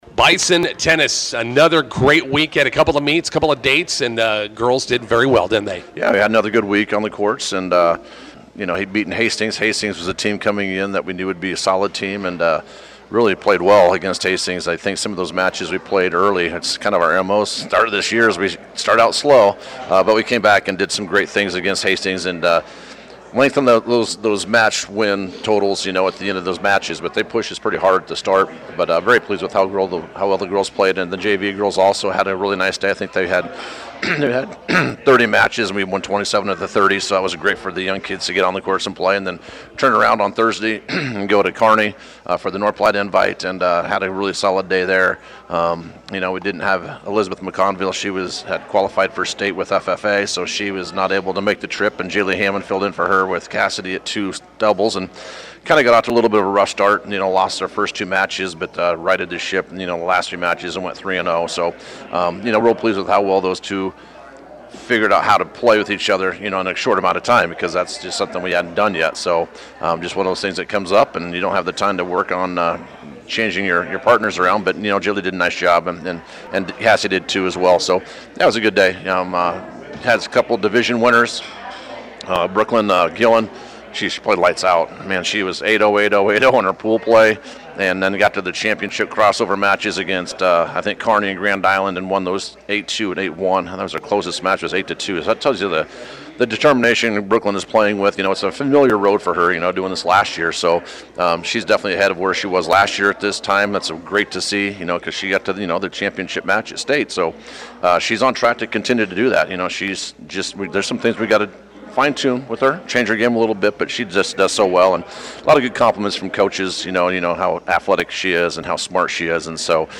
INTERVIEW: Bison Tennis begins busy week with dual vs. Gothenburg today.